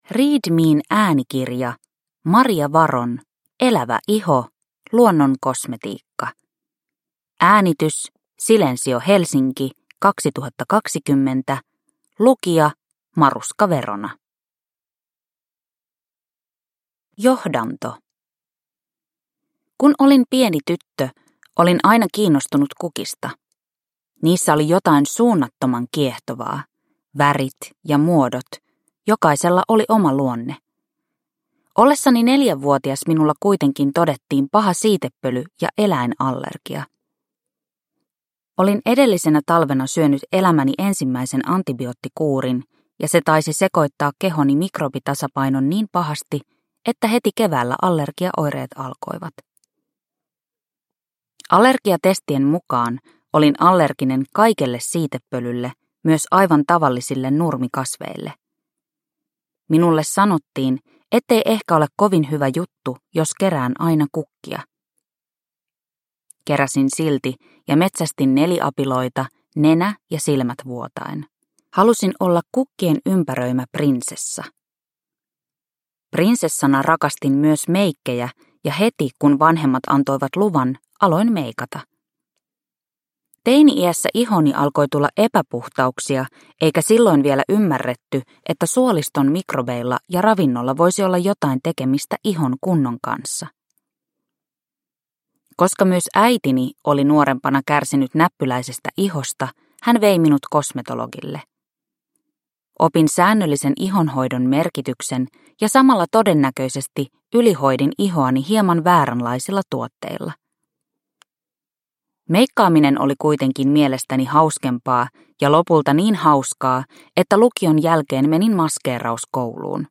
Elävä iho – Luonnon kosmetiikka – Ljudbok